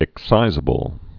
(ĭk-sīzə-bəl)